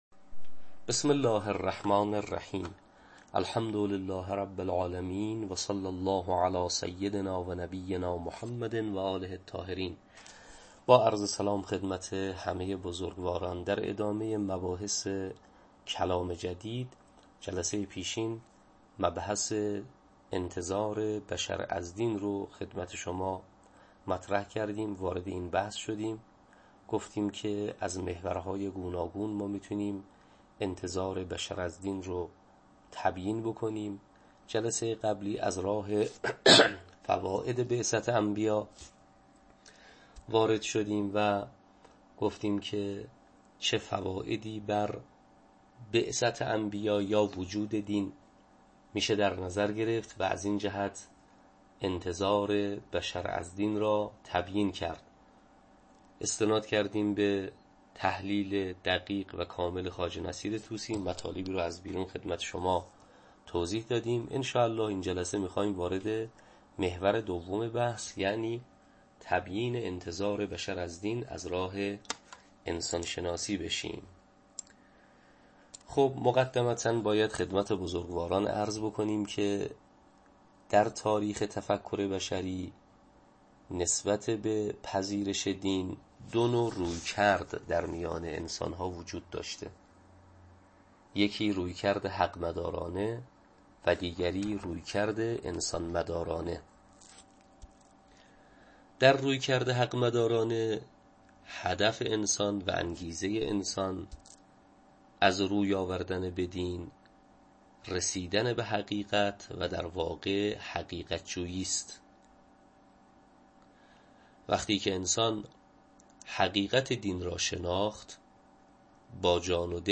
تدریس کلام جدید